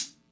Closed Hats
Hat (12).wav